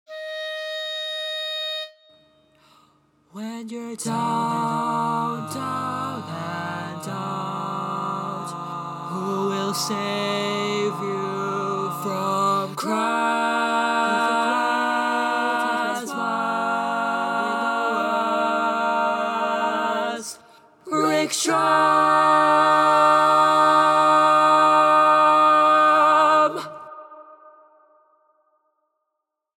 Key written in: E♭ Major
How many parts: 4
Type: Barbershop